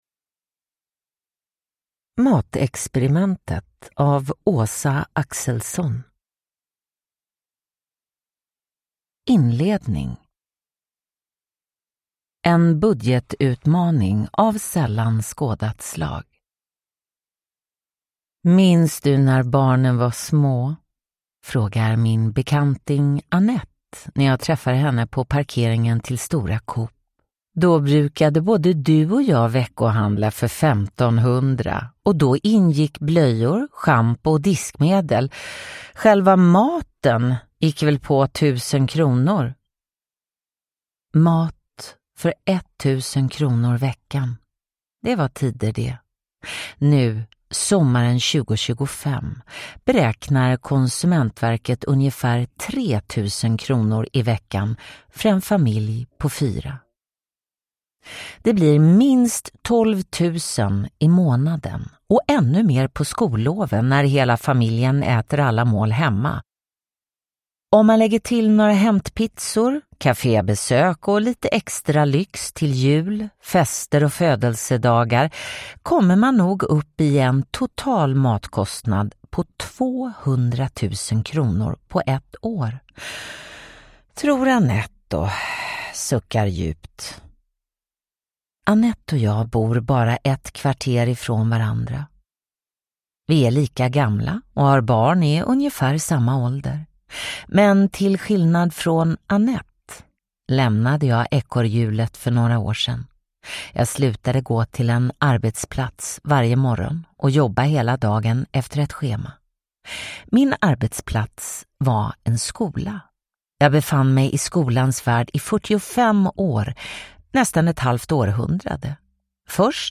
Matexperimentet : kan en familj äta för tusen kronor i veckan – Ljudbok